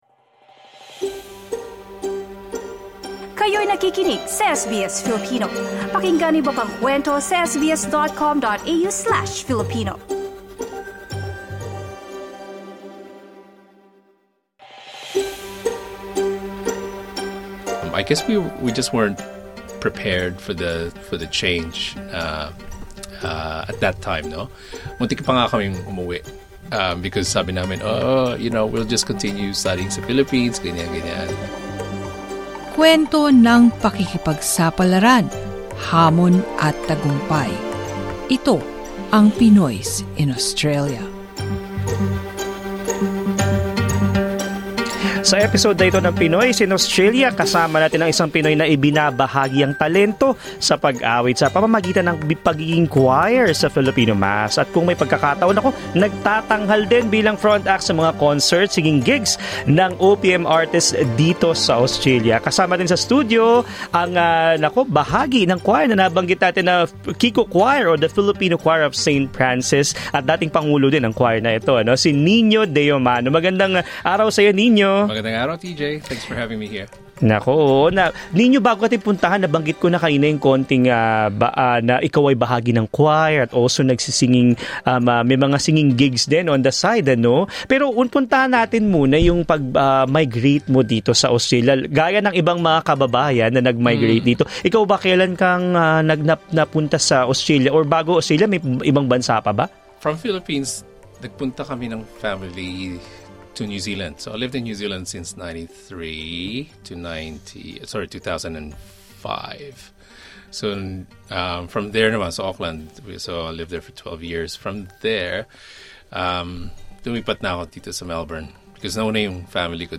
In an interview with SBS Filipino